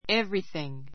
everything 中 A1 évriθiŋ エ ヴ リすィン ぐ 代名詞 すべての事[物] , 何もかも, 万事 ばんじ ⦣ 単数扱 あつか い. ✓ POINT 一つ一つを念頭に置きながらもそれらを全部ひっくるめていう時の言葉.